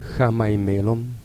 klik op het woord om de uitspraak te beluisteren